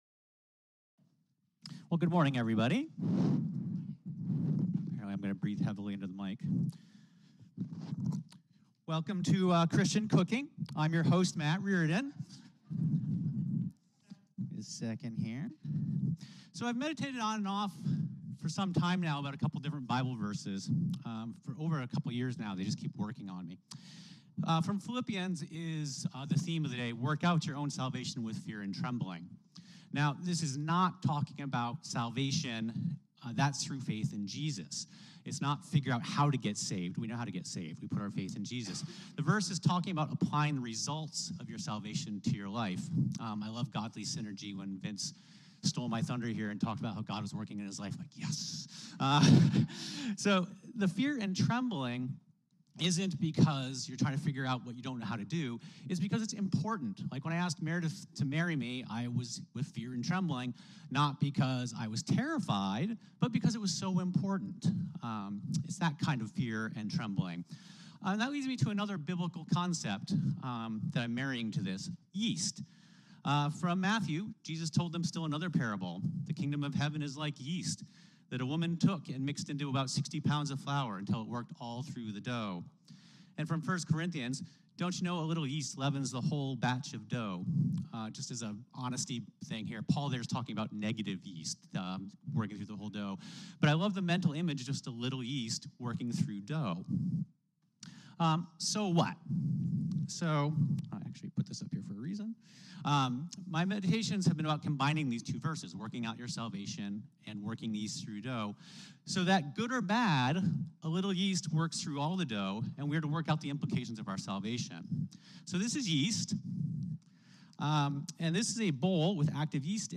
Worship Service (live recording) Sermon